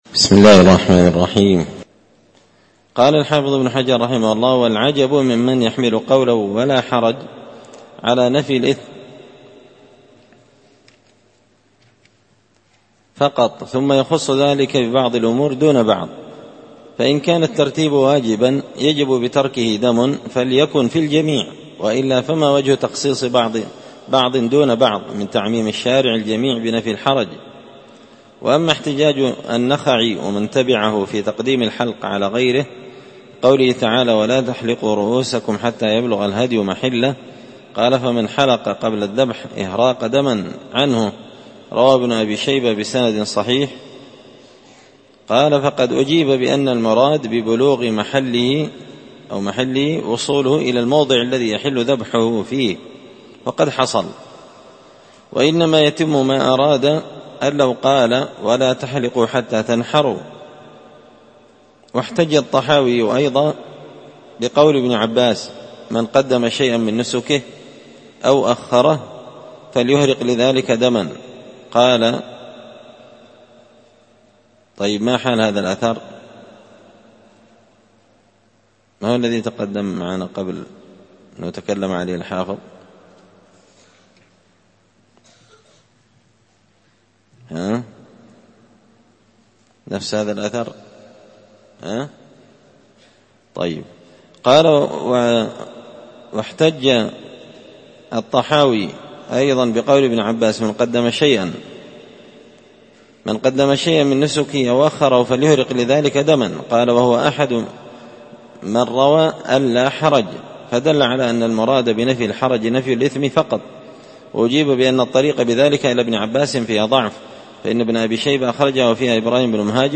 كتاب الحج من شرح صحيح البخاري – الدرس 117